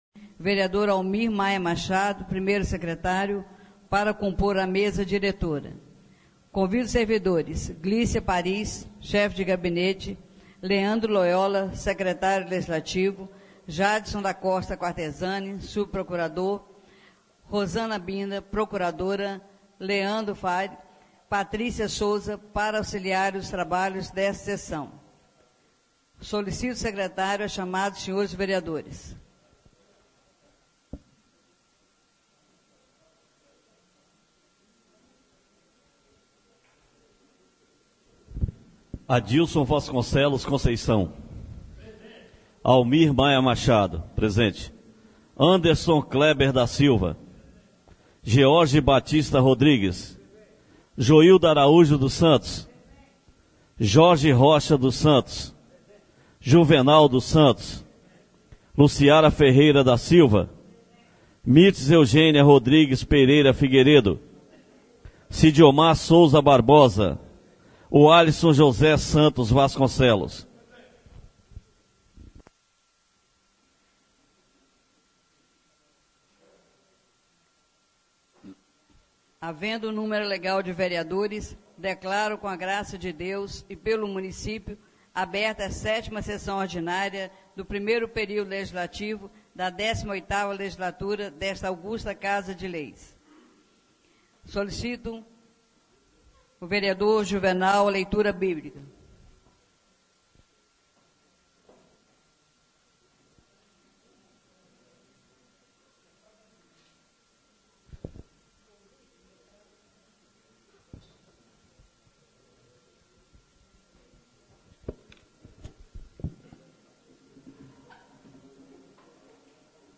7ª (SÉTIMA) SESSÃO ORDINÁRIA DO DIA 18 DE MAIO DE 2017 BRAÇO DO RIO